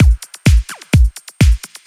Electrohouse Loop 128 BPM (3).wav